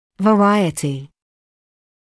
[və’raɪətɪ][вэ’райэти]разнообразие; множество